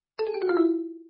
铁匠-锻造失败.mp3